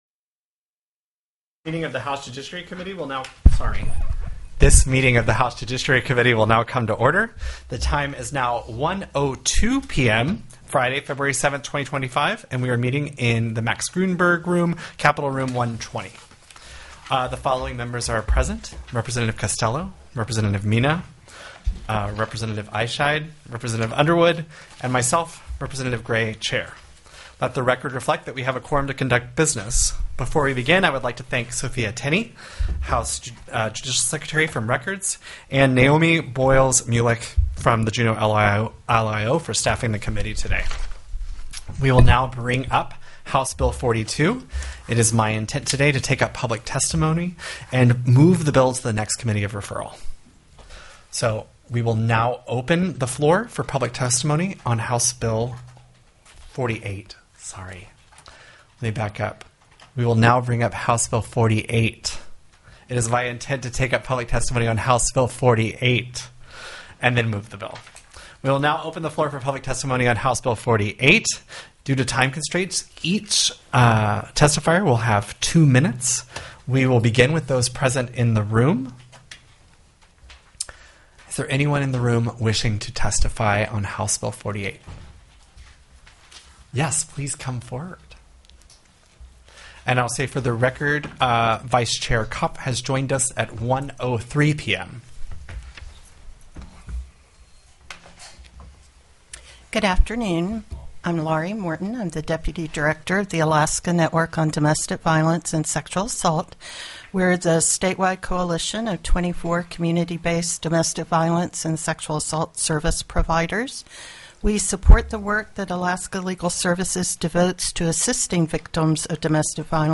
The audio recordings are captured by our records offices as the official record of the meeting and will have more accurate timestamps.
+ Bills Previously Heard/Scheduled TELECONFERENCED